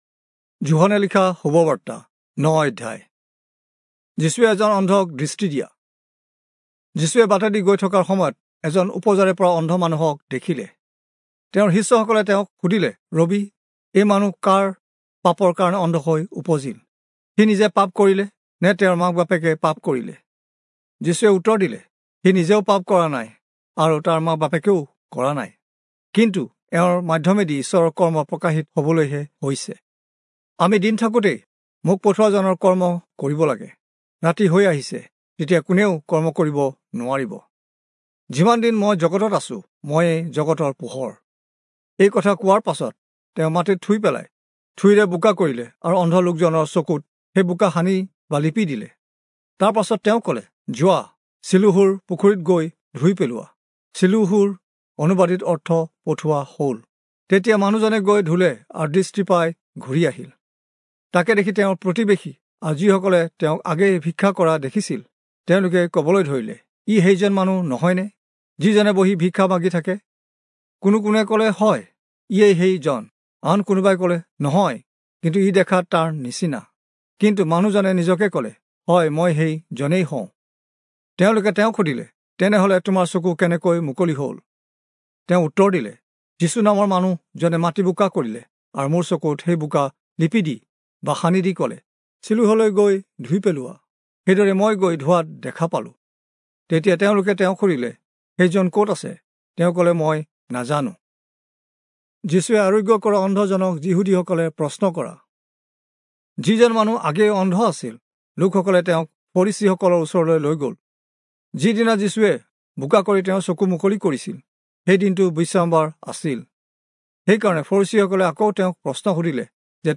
Assamese Audio Bible - John 1 in Ervkn bible version